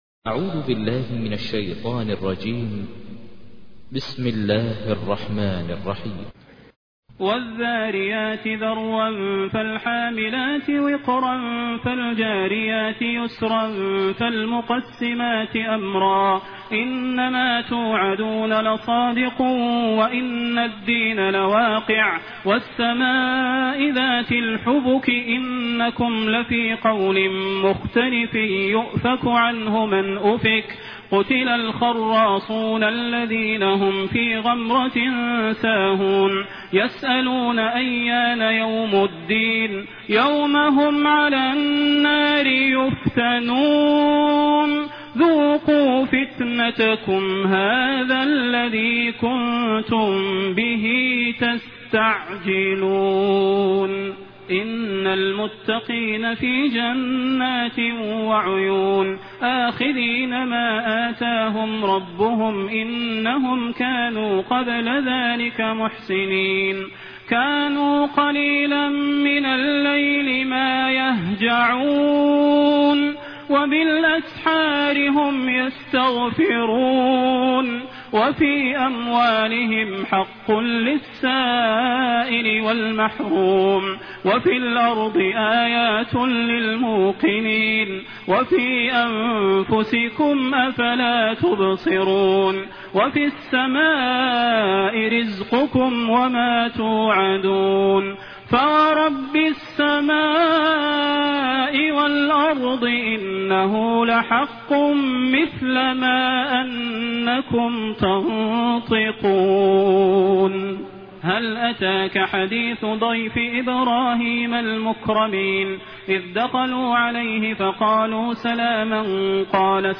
تحميل : 51. سورة الذاريات / القارئ ماهر المعيقلي / القرآن الكريم / موقع يا حسين